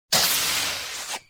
airlock.wav